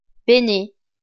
Fichier audio de prononciation du projet Lingua Libre